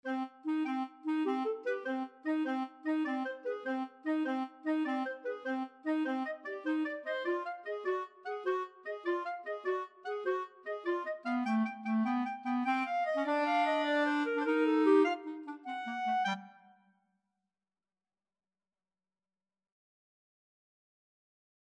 3/8 (View more 3/8 Music)
Classical (View more Classical Clarinet Duet Music)